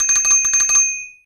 Add bicycle sounds (CC0)
sounds_bicycle_bell.ogg